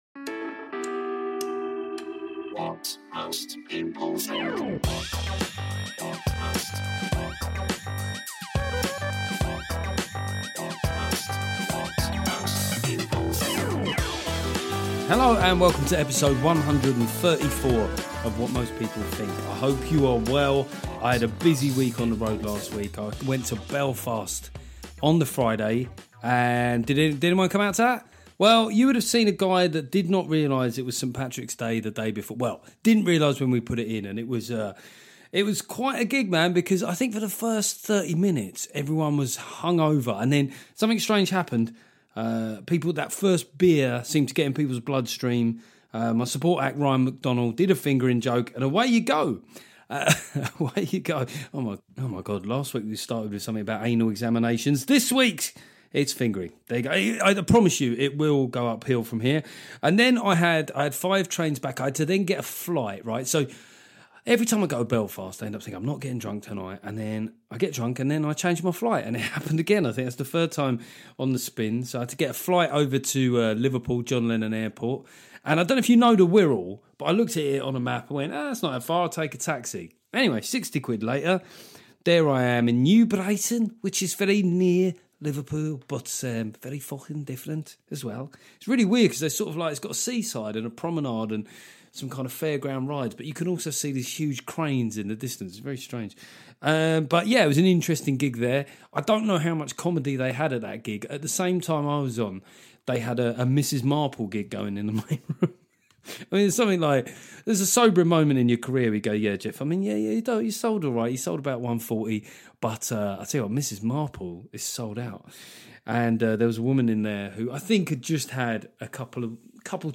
It's a solo episode this week as there's so much to talk about. I rant about a current lack of objectivity in reporting the the government.